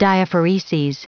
Prononciation du mot diaphoreses en anglais (fichier audio)
Prononciation du mot : diaphoreses